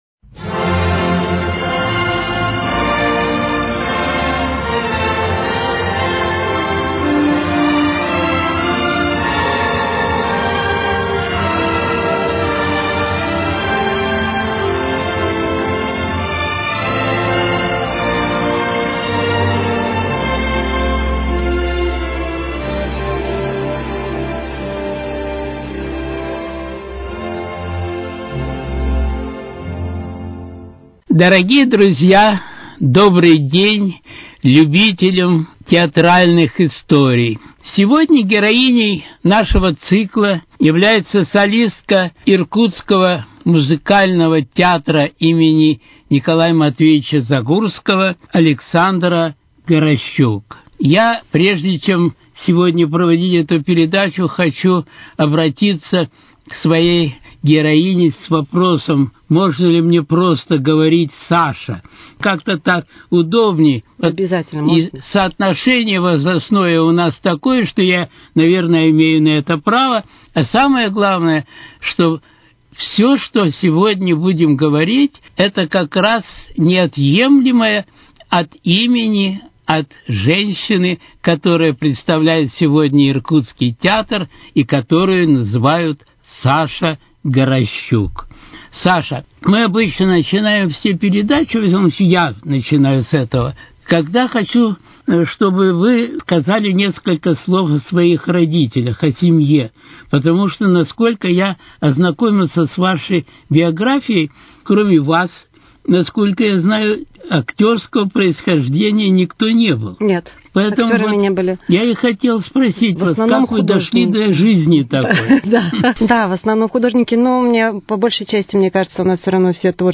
Без категории Театральные истории: Беседа с солисткой Музыкального театра им.